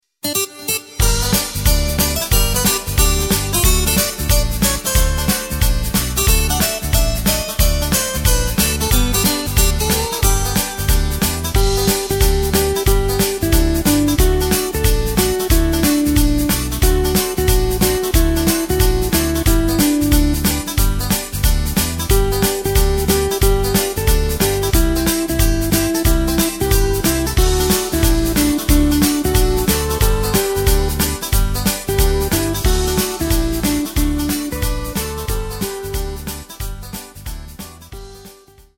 Takt:          4/4
Tempo:         182.00
Tonart:            G
Country-Beat aus dem Jahr 1995!